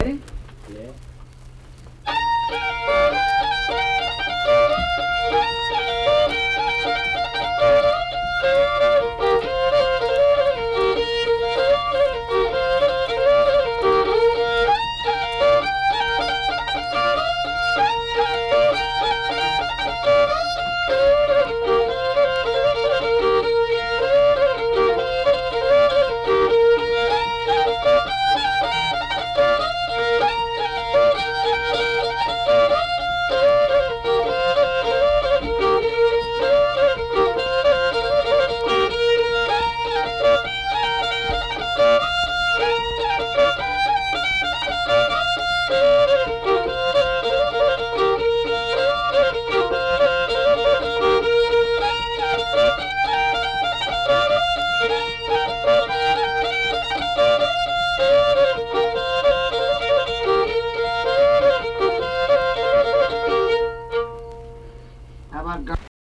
Unaccompanied fiddle music and accompanied (guitar) vocal music performance
Instrumental Music
Fiddle
Braxton County (W. Va.)